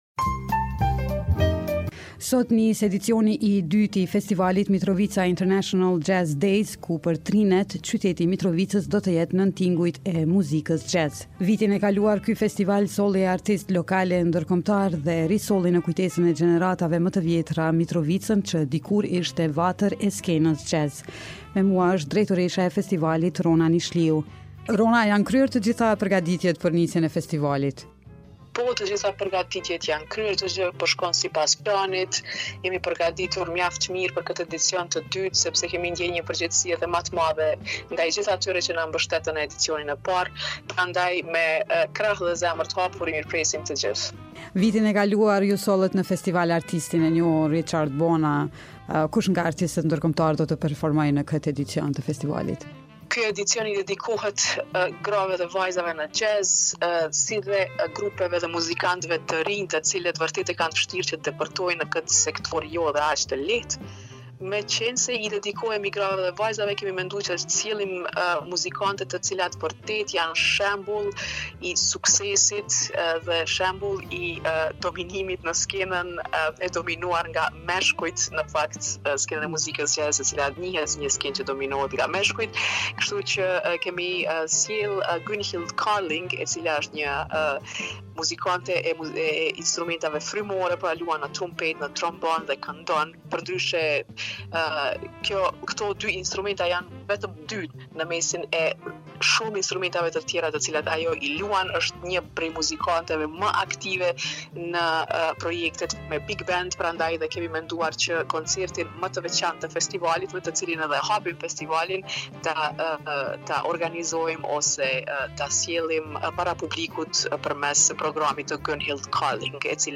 Drejtoresha e festivalit, Rona Nishliu, i tha Radios Evropa e Lirë se ky edicion u kushtohet grave dhe vajzave në jazz, si dhe muzikantëve të rinj.